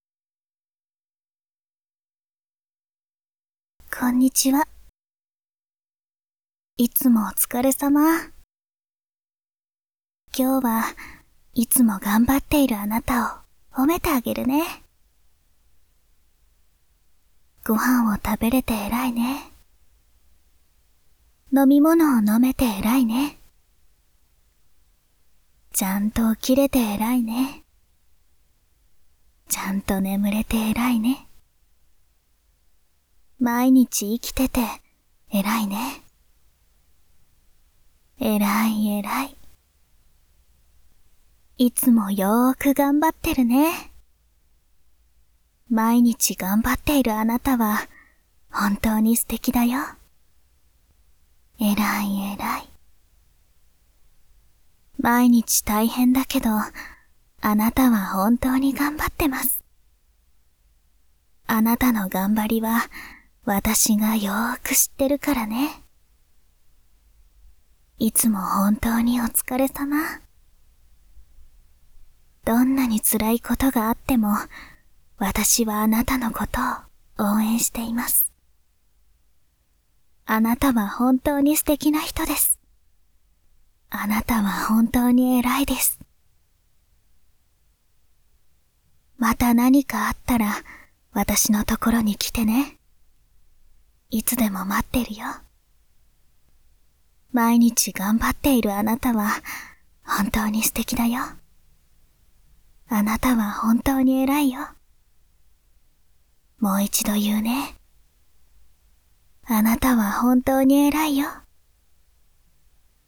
【癒しボイス】あなたのことを褒めてくれる女の子
纯爱/甜蜜 日常/生活 温馨 萌 健全 治愈 皆大欢喜 催眠音声